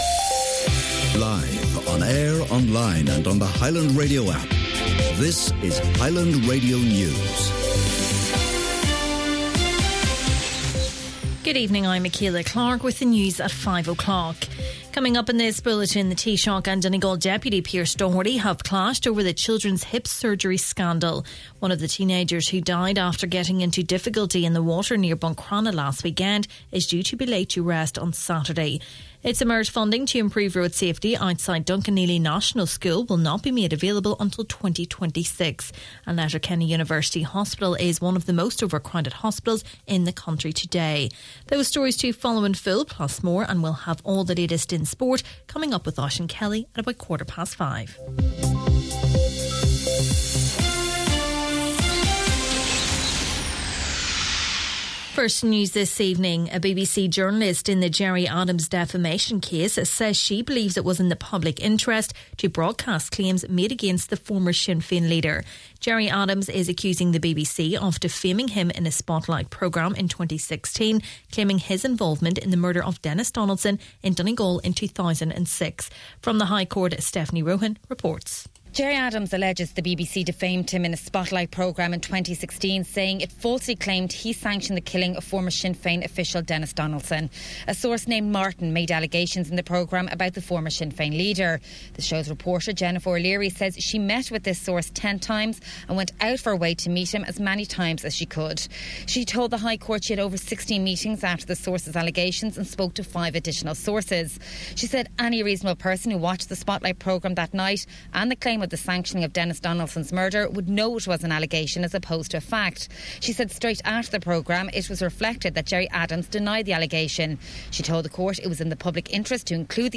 Main Evening News, Sport and Obituaries – Wednesday, May 14th